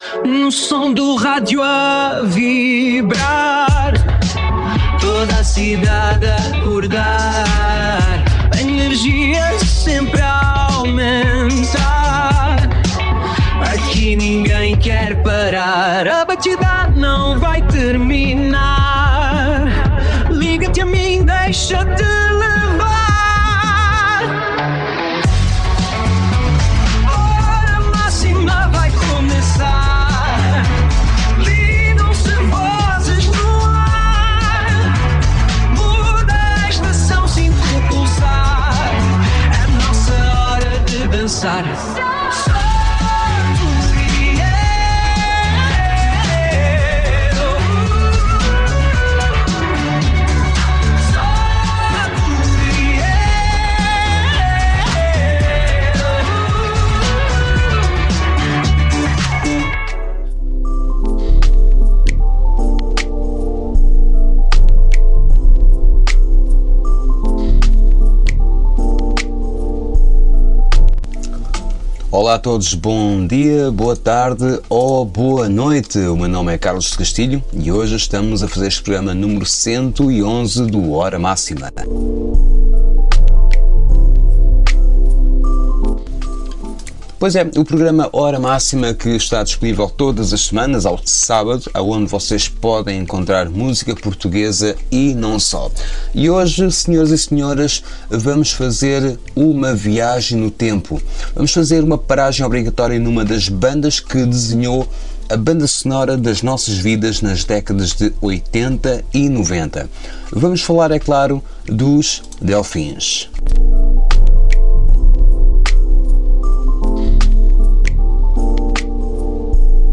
On this show I’m going to tell a bit and play some songs of this amazing and iconic portuguese bands.